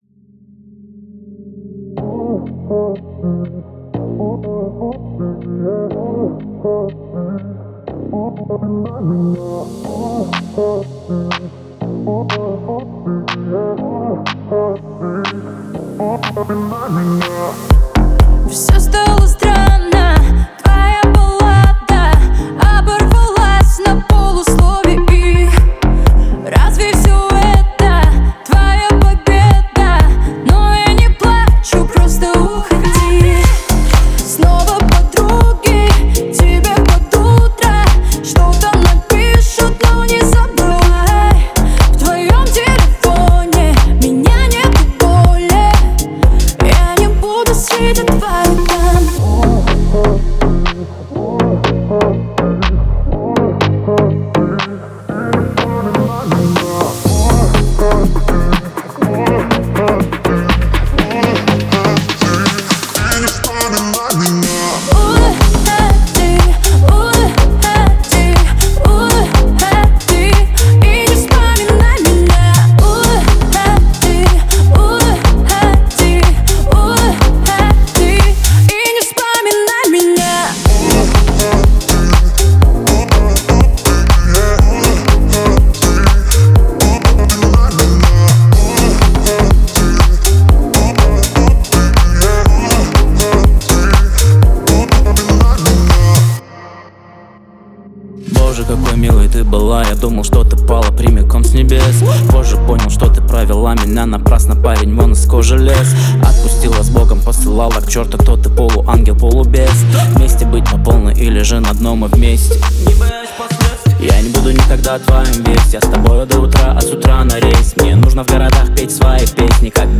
это яркий пример современного русского хип-хопа.